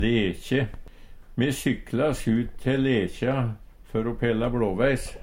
lekje - Numedalsmål (en-US)